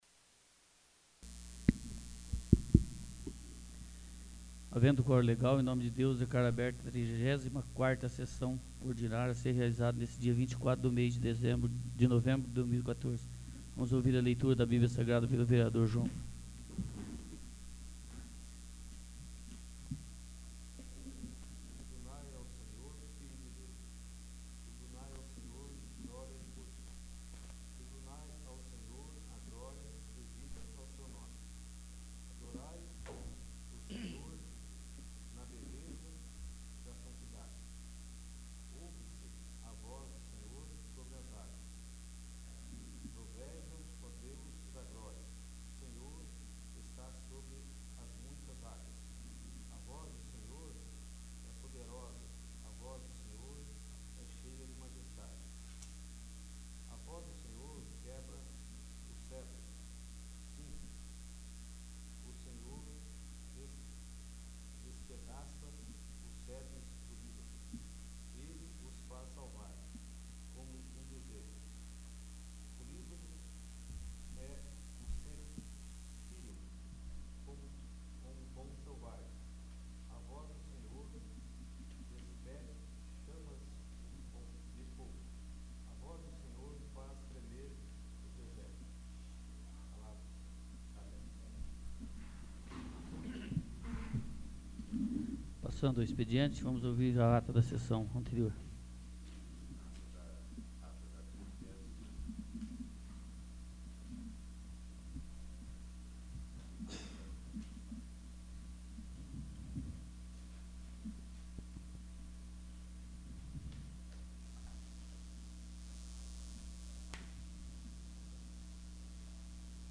34º. Sessão Ordinária